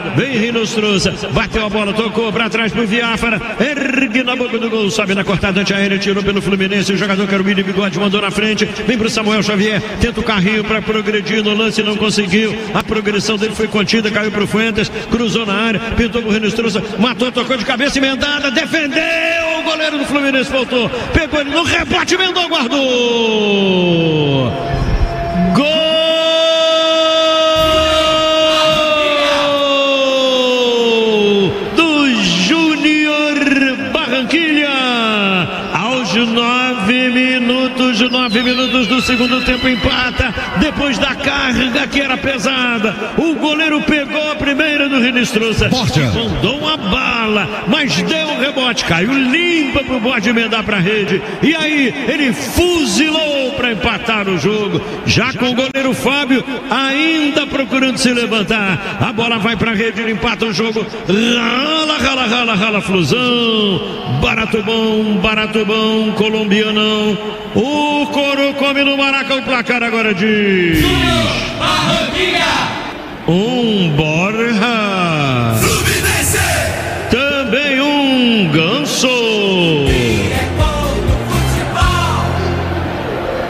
Ouça os gols da vitória do Fluminense sobre o Junior Barranquilla com a narração de Luiz Penido